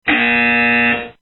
buzzer
Category: Sound FX   Right: Personal